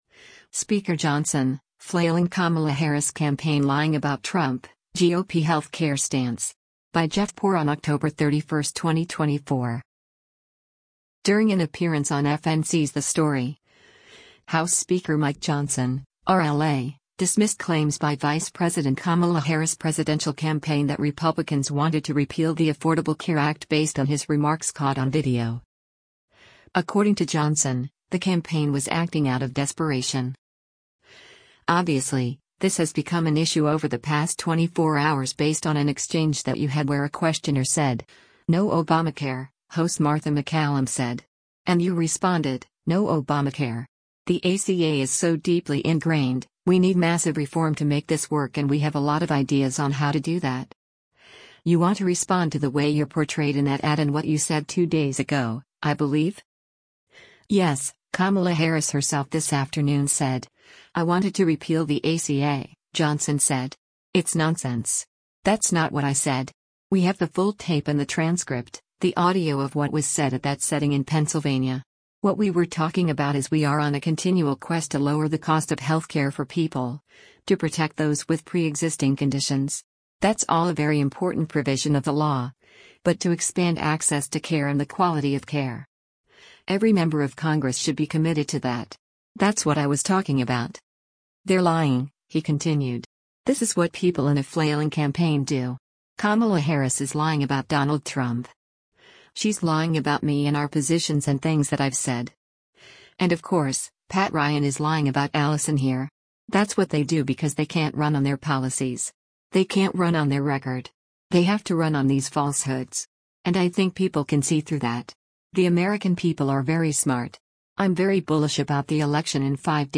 During an appearance on FNC’s “The Story,” House Speaker Mike Johnson (R-LA) dismissed claims by Vice President Kamala Harris’ presidential campaign that Republicans wanted to repeal the Affordable Care Act based on his remarks caught on video.